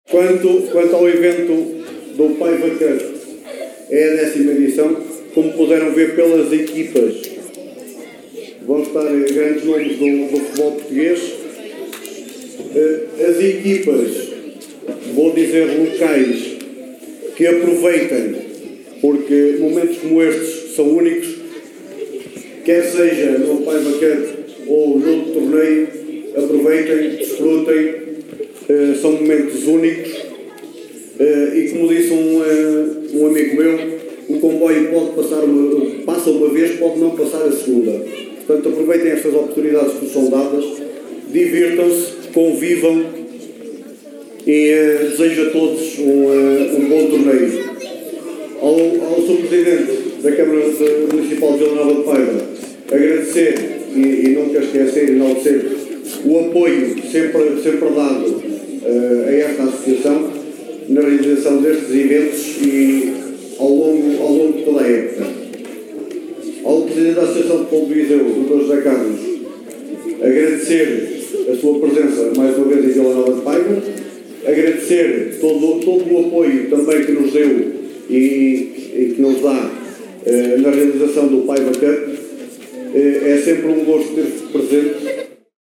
Na tarde deste sábado, 12 de abril, decorreu no Auditório Municipal de Vila Nova de Paiva, a apresentação oficial do 10º Torneio de Futebol Infantil – Paiva Cup 2025, que se vai realizar na próxima sexta-feira, 18 de abril, nos escalões de sub 9 e sub 10 e sábado, dia 19, nos escalões de sub 12 e sub 13.